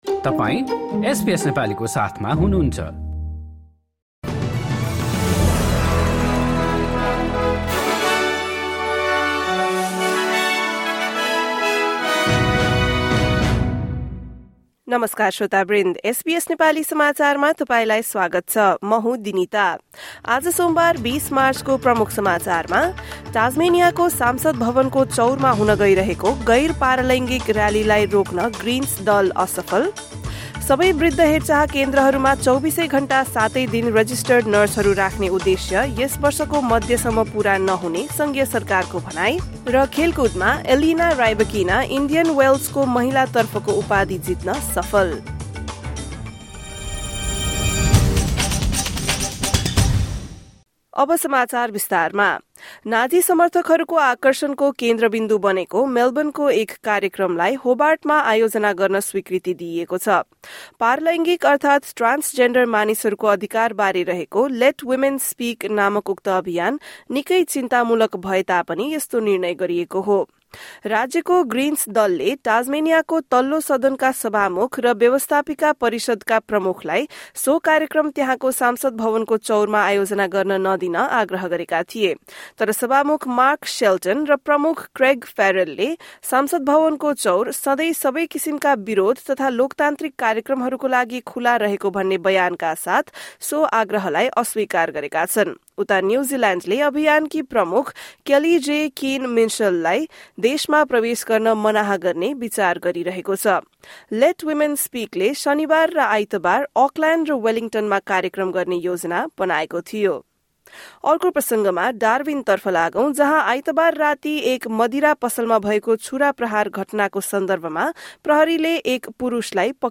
Listen to the latest news headlines from Australia in Nepali. In this bulletin: the Greens fail to stop an anti-trans rally from going ahead on the steps of Tasmania's Parliament, Federal government says it does not expect its goal of having 24/7 registered nurses in all aged care residences to be completely met by mid-year, and Wimbledon champion Elena Rybakina claims the Indian Wells women's title.